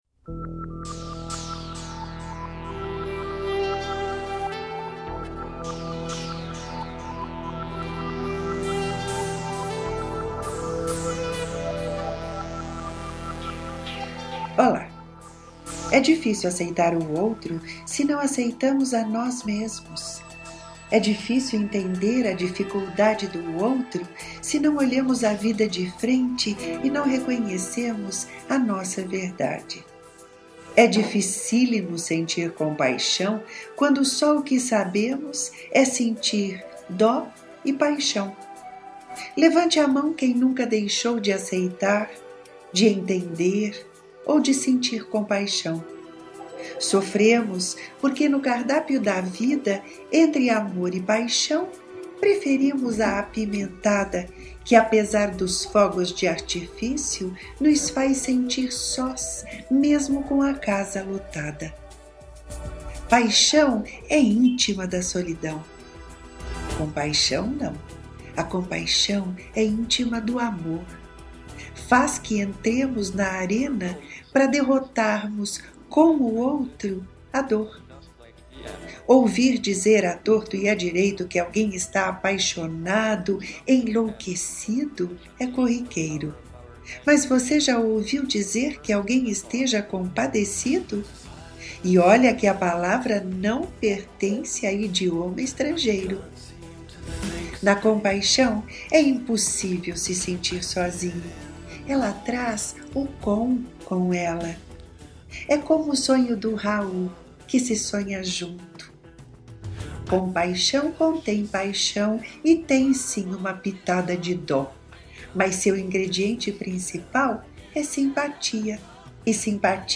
Ouça De compaixão e dó na voz da autora